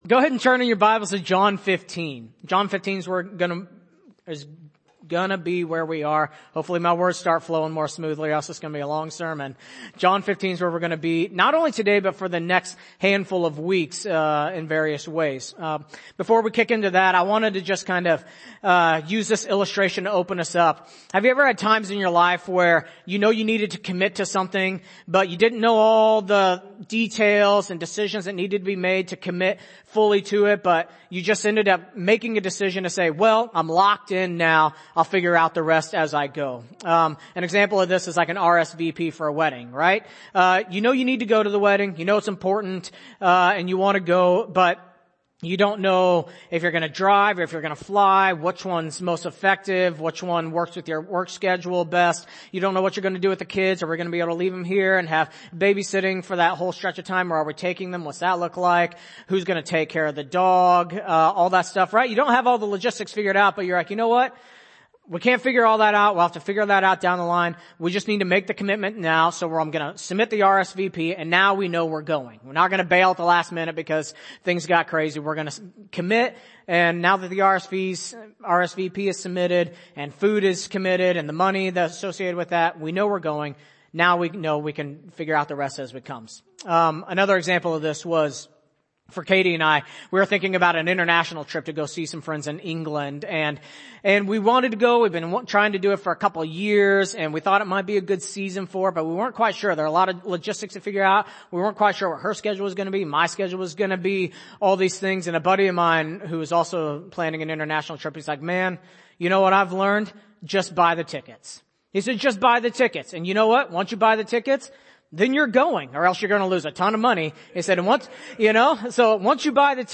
January 1, 2023 (Sunday Morning)